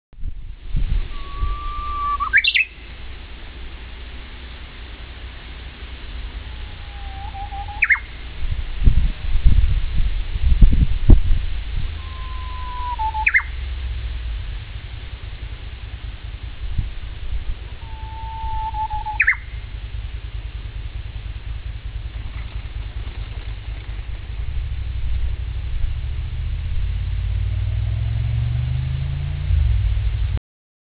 大沼温泉のバス停に降りると辺りには誰もいなく静かな大沼が目の前に広がります。